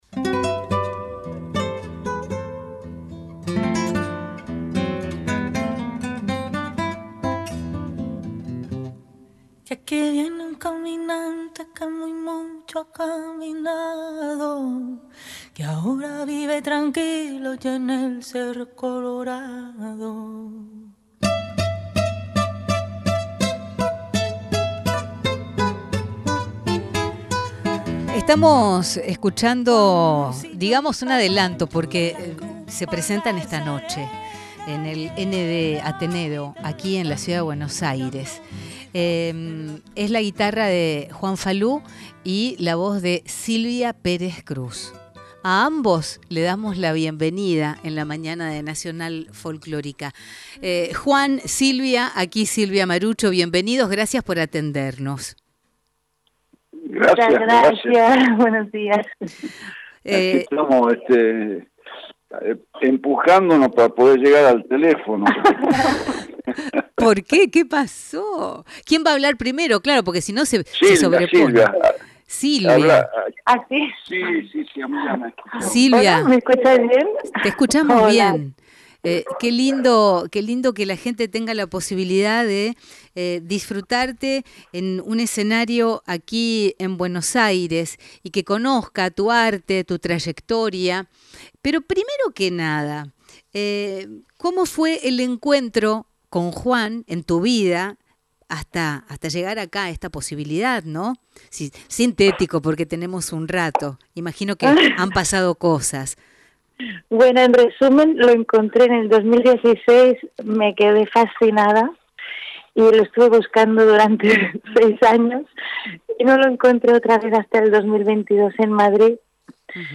Entrevista con Juan Falú por el espectáculo de Mojones